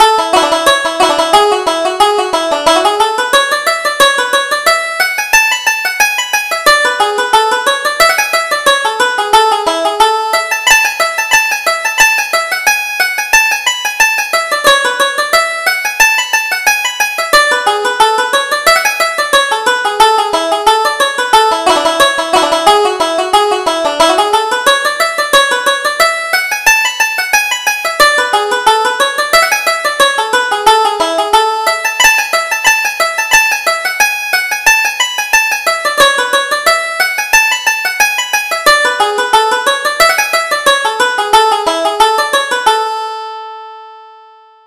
Reel: Johnny's Welcome Home